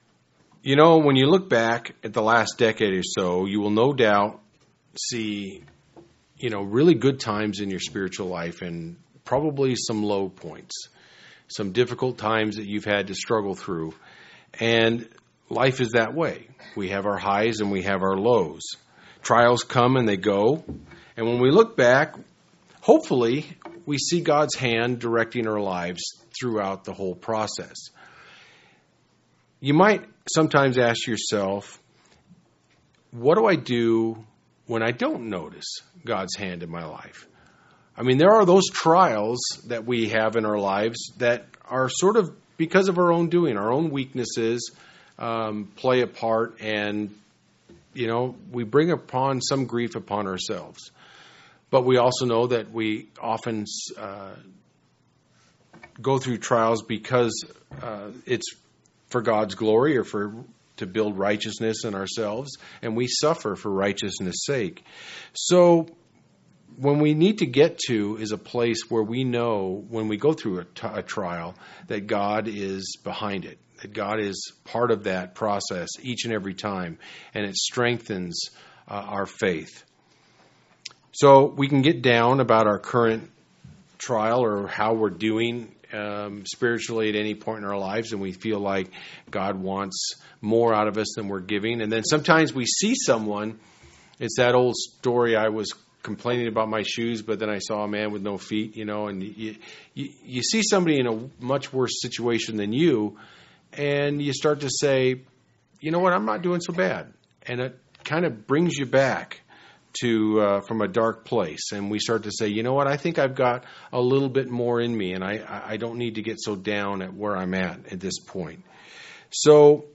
Sermons
Given in Central Oregon Medford, OR